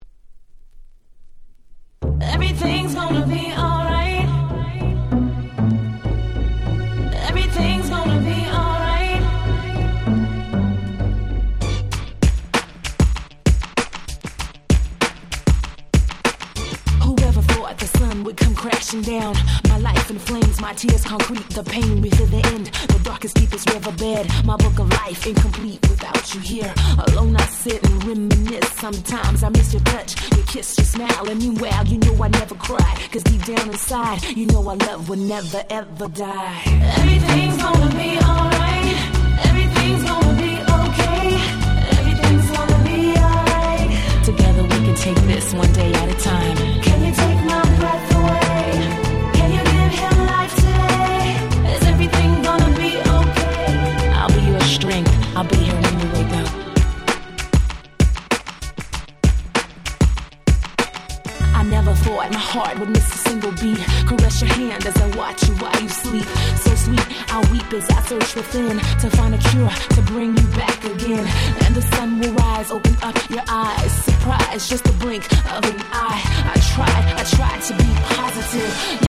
03' Nice R&B !!